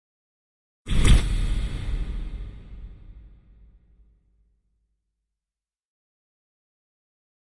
Impact Muted Hit Reverb - Version 2